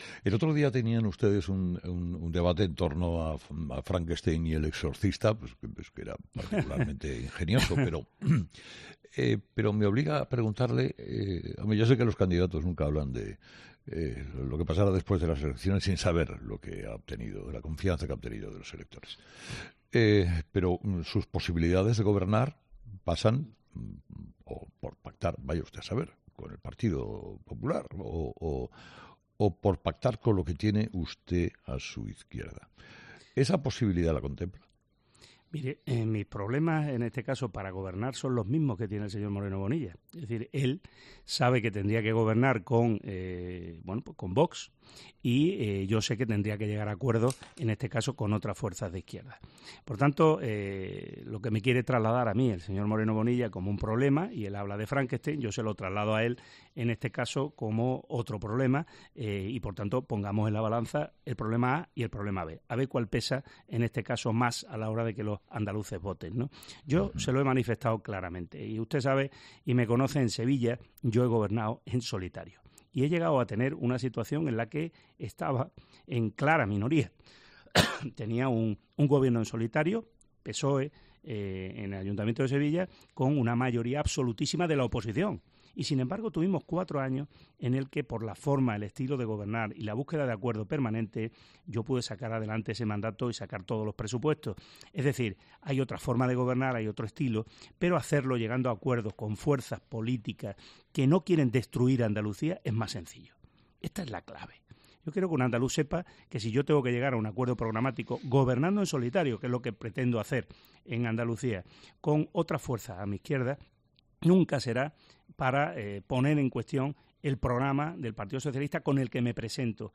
Juan Espadas, candidato del Partido Socialista andaluz a las elecciones del 19 de junio, ha sido el protagonista de la entrevista política de este viernes en "Herrera en COPE".